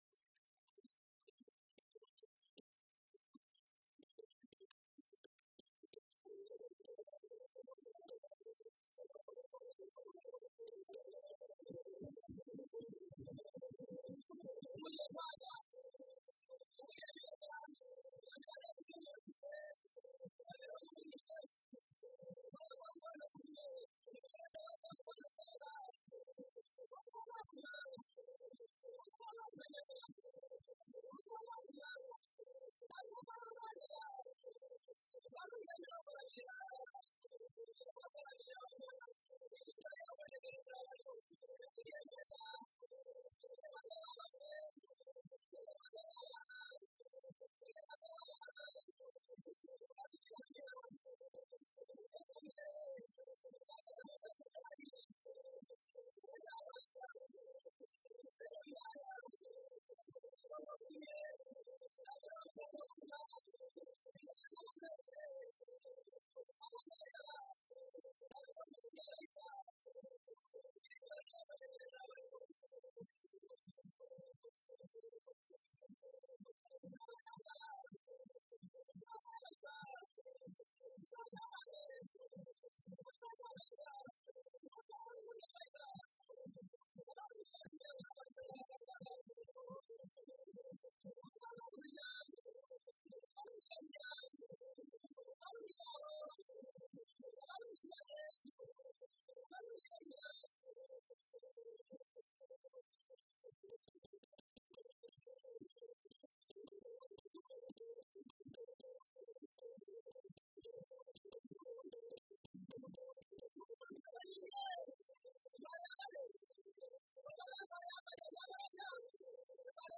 Folk songs, Ganda
Field recordings
Africa Uganda Kampala f-ug
96000Hz 24Bit Stereo